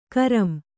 ♪ karam